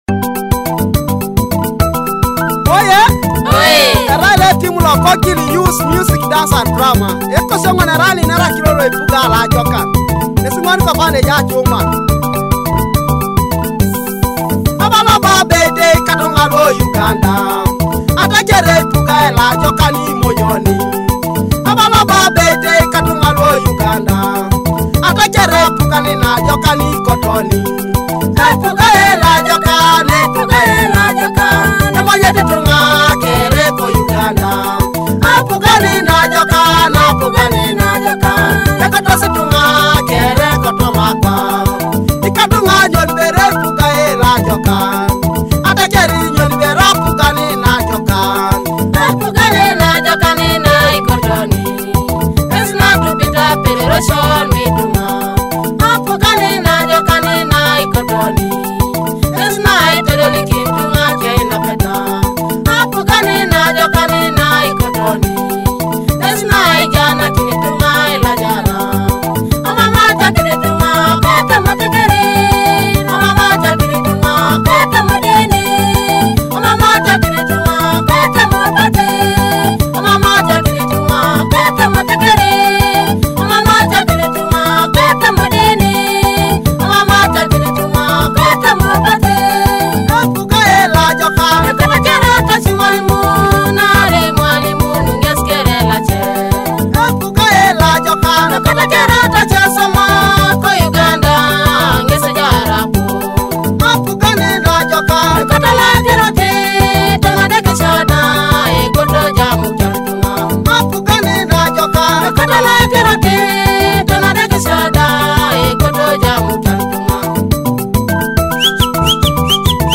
featuring joyful Ateso cultural and traditional rhythms
Akogo (thumb piano)